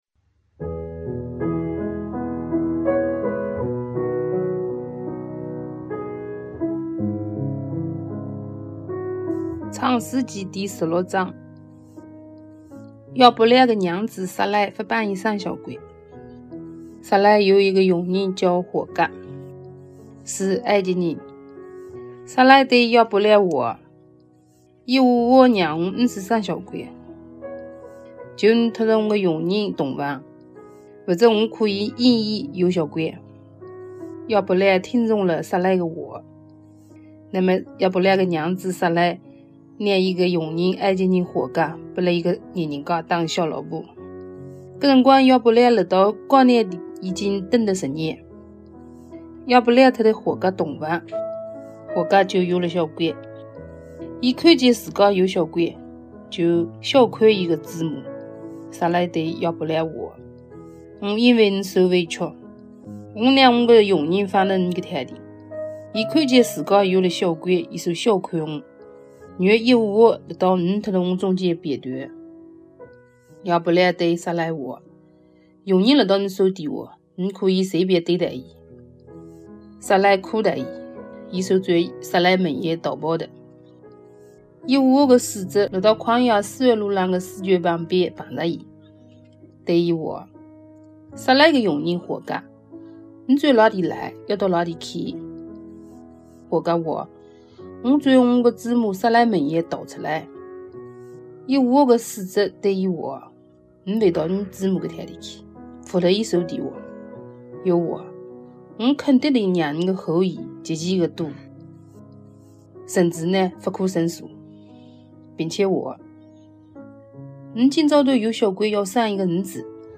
语言：海门话